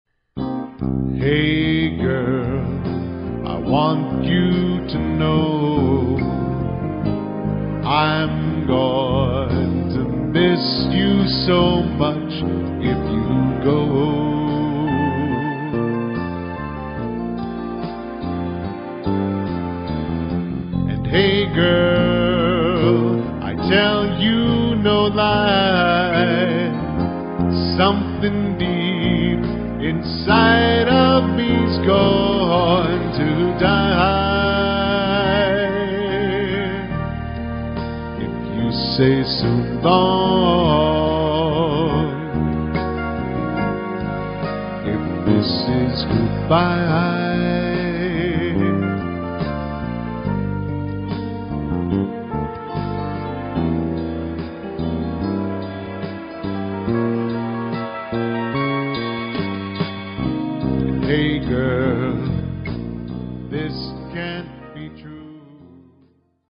Pop Ballad